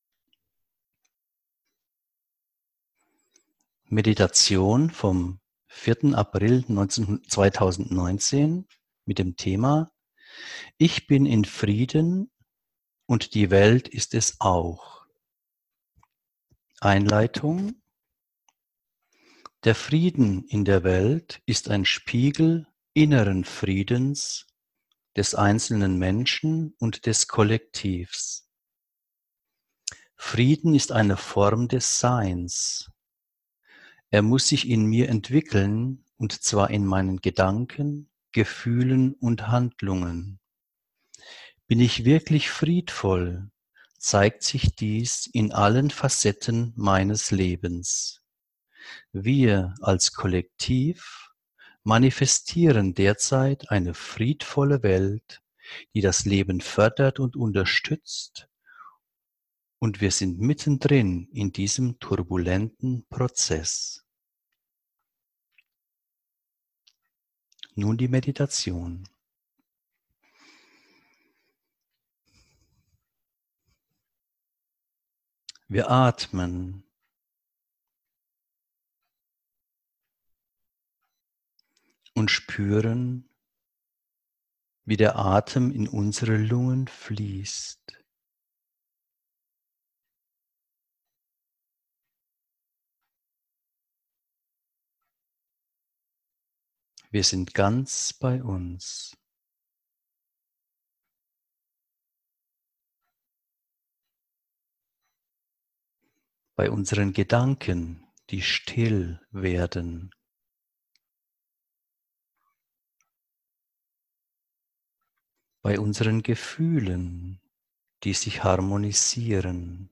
Dies ist eine Friedensmeditation.